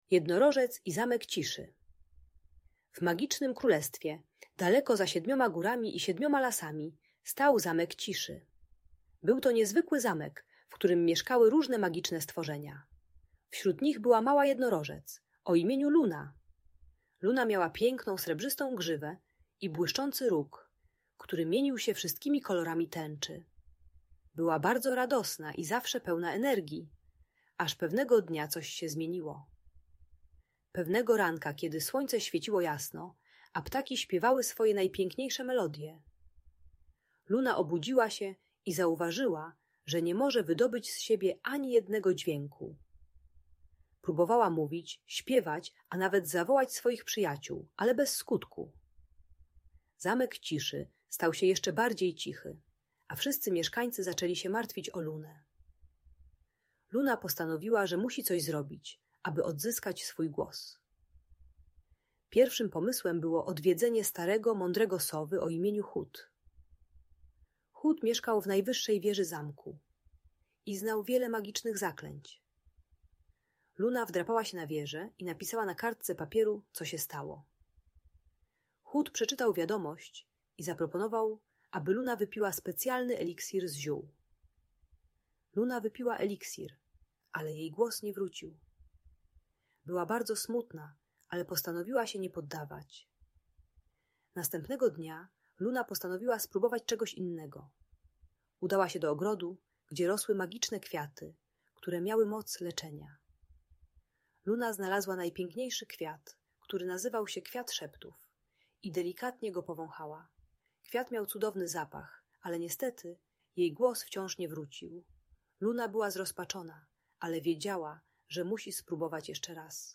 Magiczna historia o jednorożcu i Zamku Ciszy - Audiobajka dla dzieci